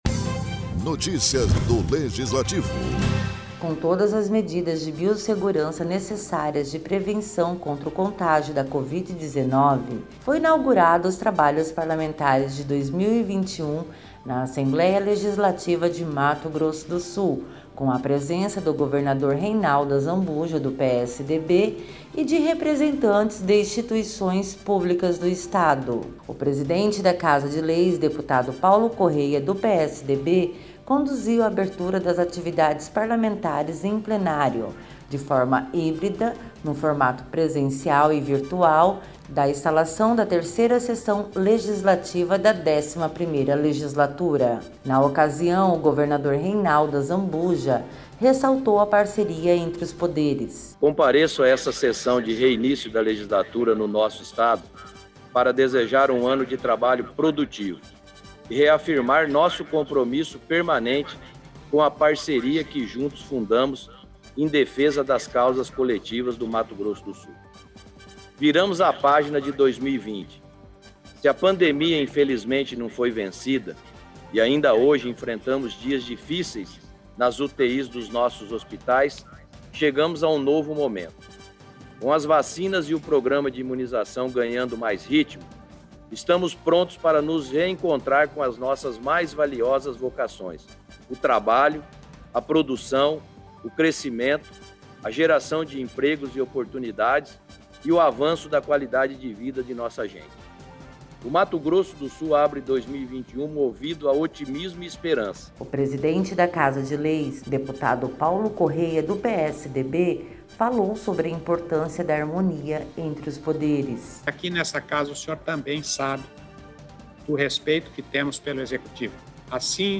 Download Produção e locução